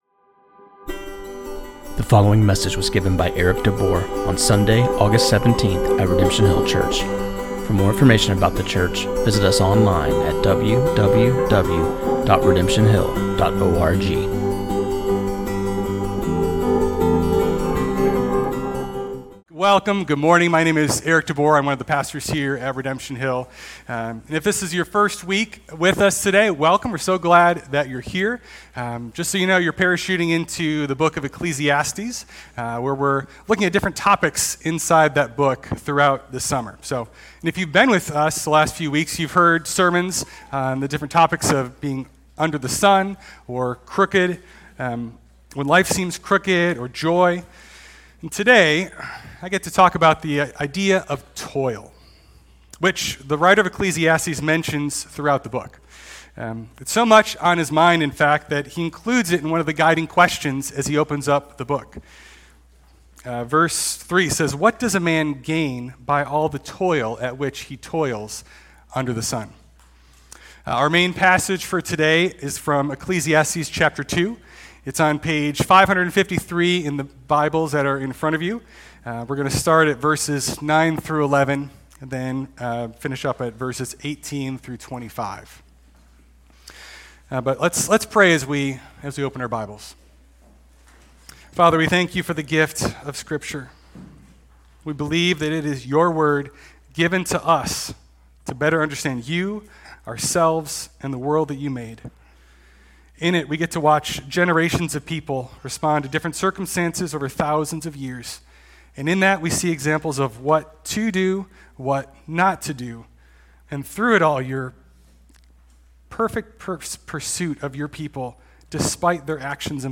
This sermon on Ecclesiastes 2:18-26 was preached